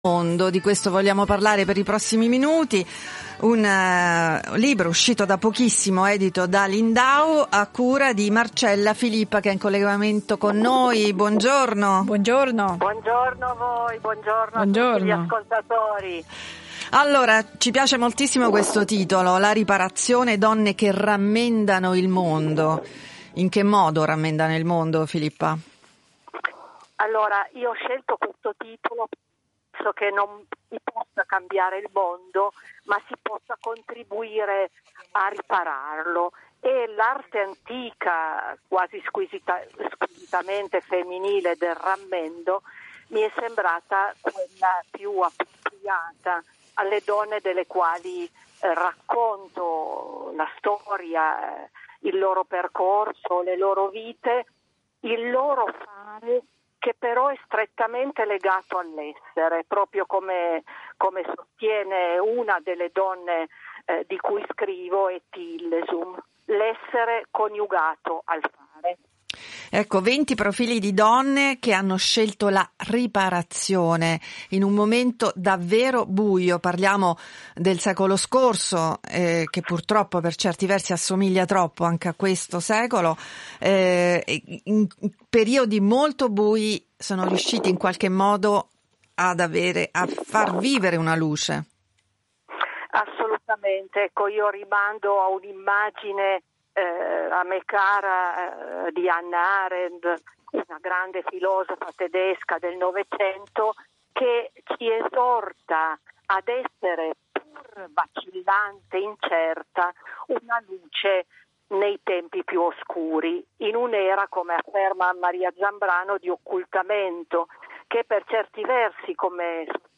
Intervista